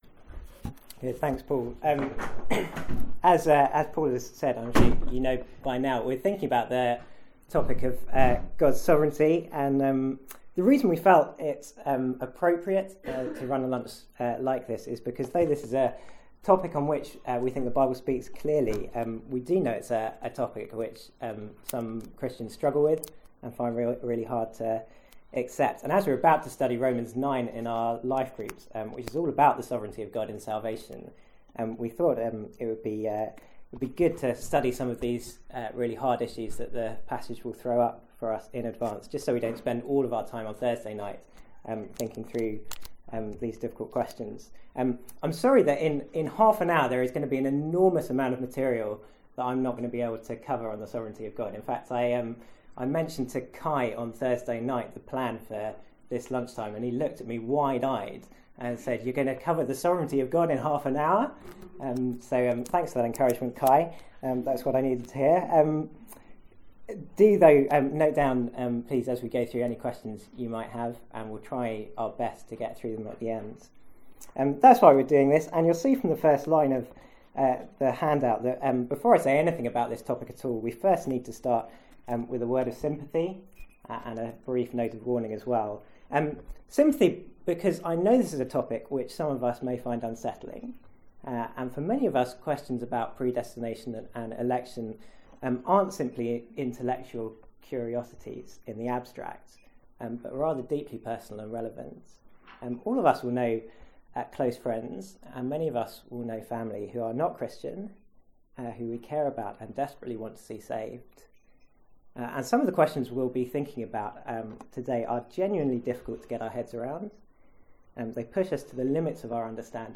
From the LifeGroups lunch on 23/2/14.